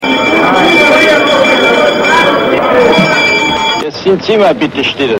Adenauer-Klingelton